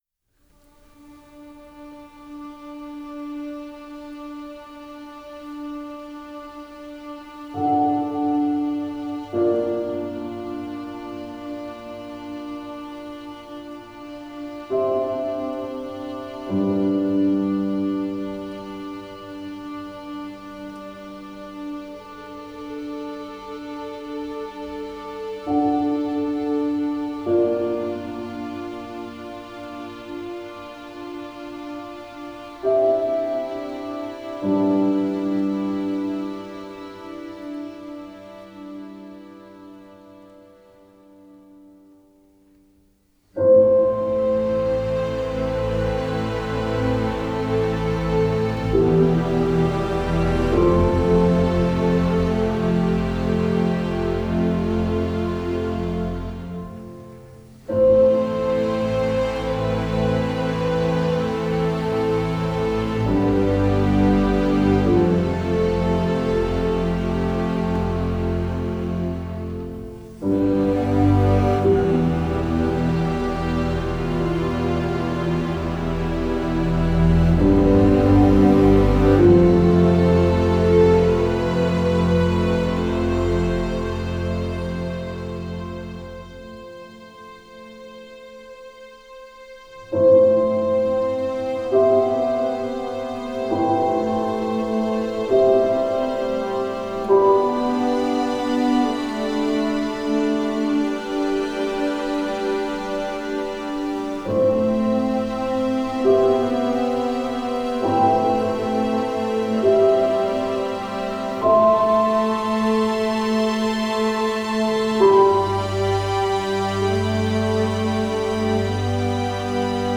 Genre: Score.